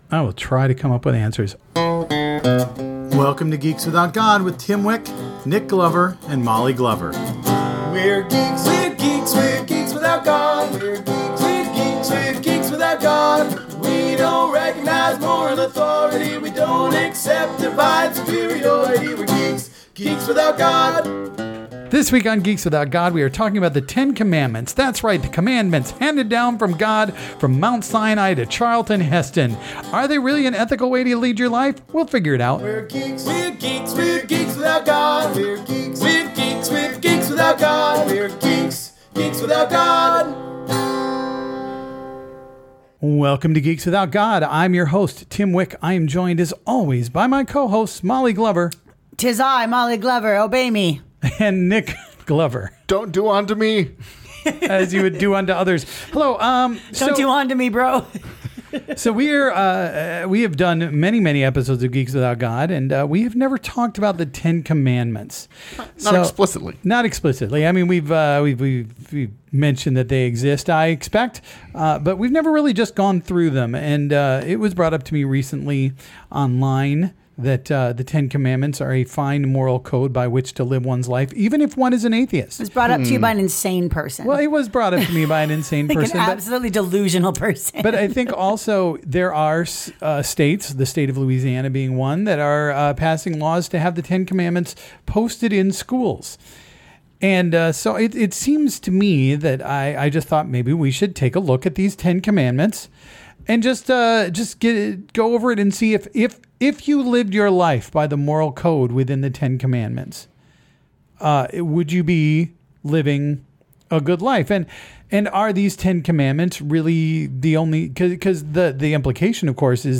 Geeks Without God is a podcast by three atheist/comedian/geeks. We'll talk about geeky stuff, atheist issues and make jokes.